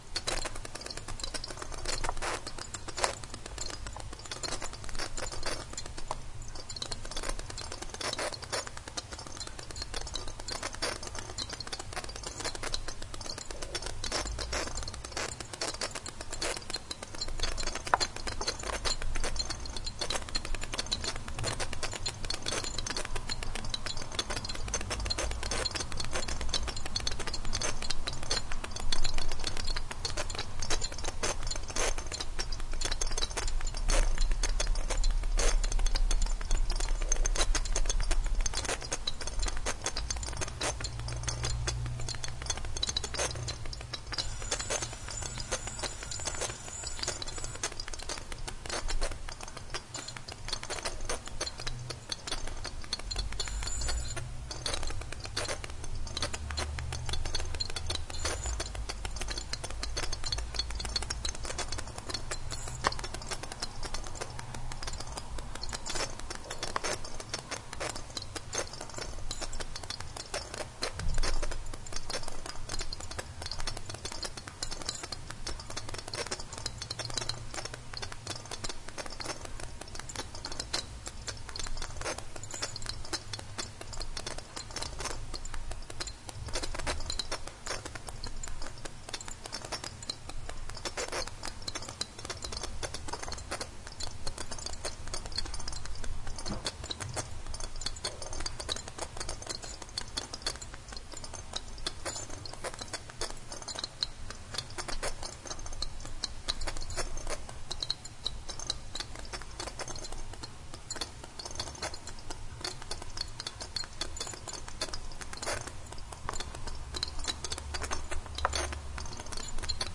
气泡02
描述：泡泡的水是用装满空气的瓶子做成的在水槽的水下这个泡泡的声音很甜用sony MD录音机和立体声话筒录制
Tag: 气泡